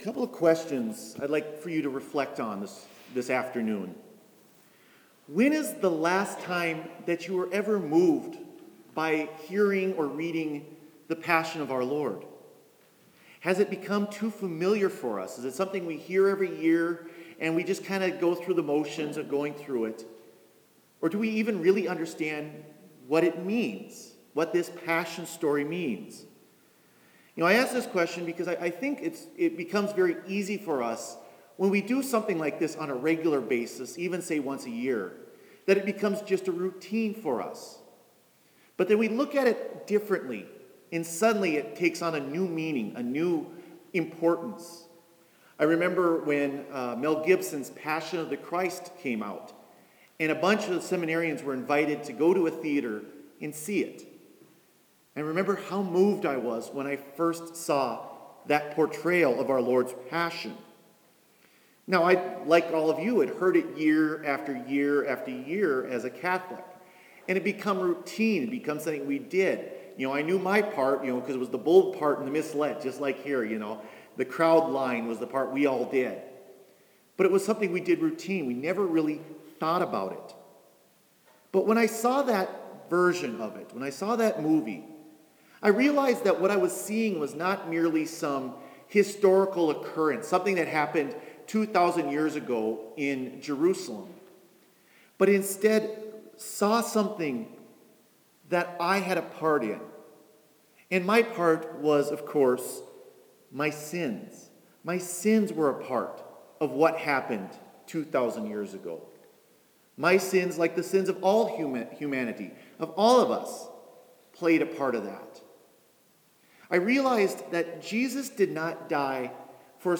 Homily for Good Friday